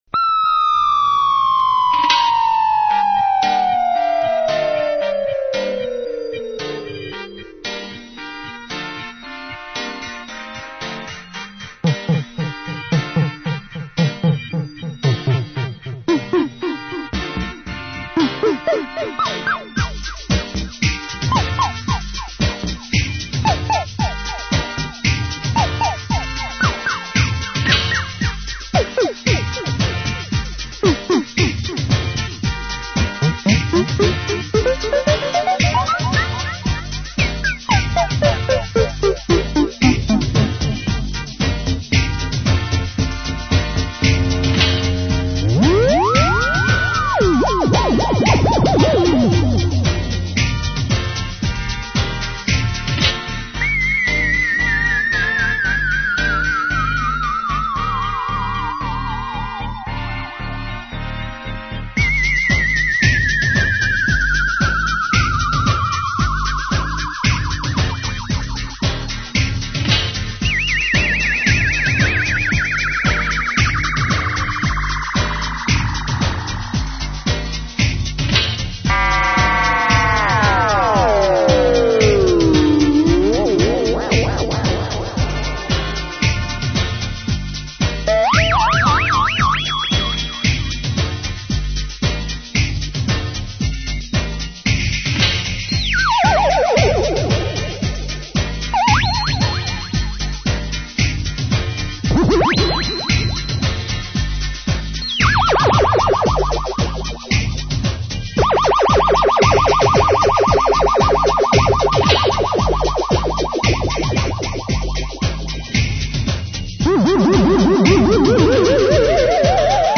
SynFex - Syndrum Sound Effects Unit
This unit makes many of the familiar Syndrum type of effects that
( No Echo delay or other processing applied - Just this unit and the music playing )